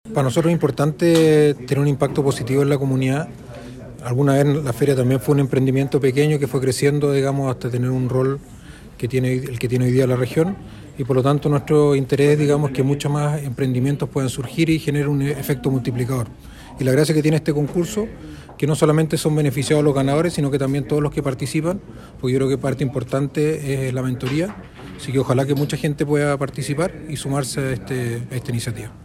En Sala de Sesiones se realizó el lanzamiento de la segunda versión del Concurso de Emprendimiento “Nada Nos Detiene”, que ejecuta la Corporación G-100, con la colaboración del municipio de Osorno y el patrocinio del “Grupo de Empresas Feria Osorno”, y que premiará con $3 millones de pesos al ganador.